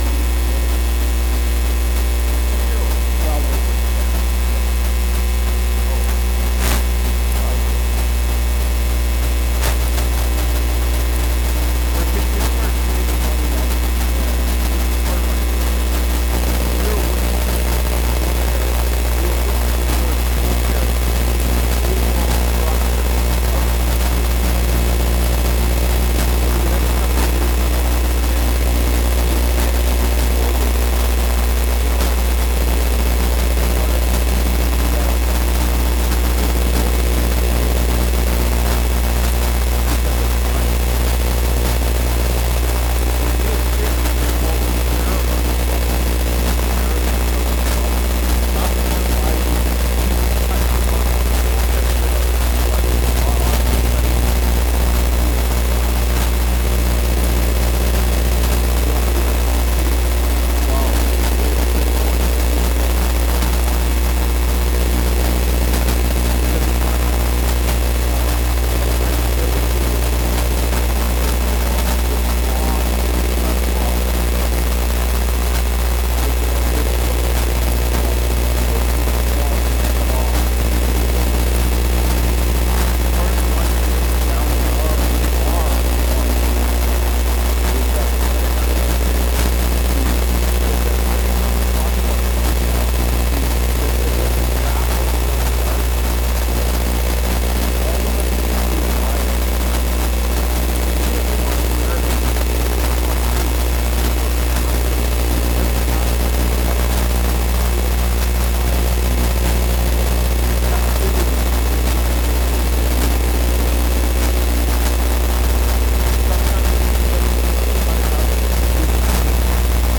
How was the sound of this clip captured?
Dec 29, 2024 Instruct One Another (Colossians 3:12-17) MP3 SUBSCRIBE on iTunes(Podcast) Notes Discussion Sermons in this Series This sermon was recorded in Salmon Arm and preached in both campuses.